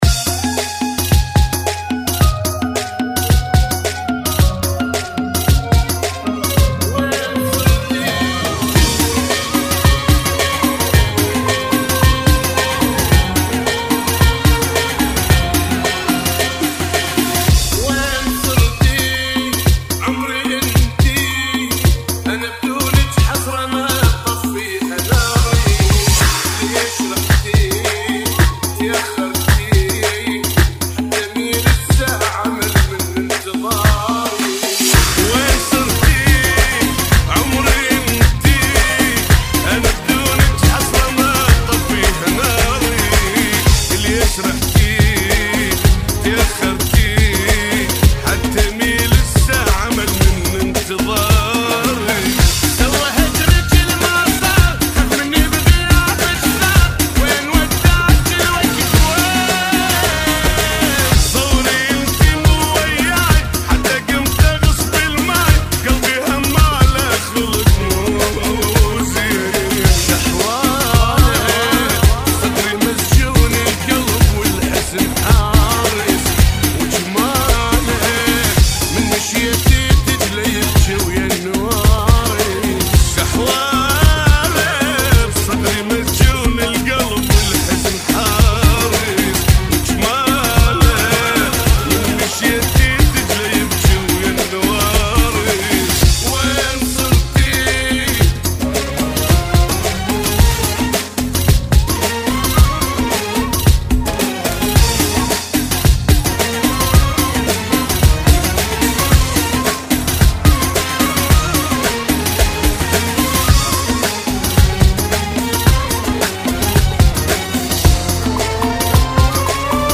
Funky [ 110 Bpm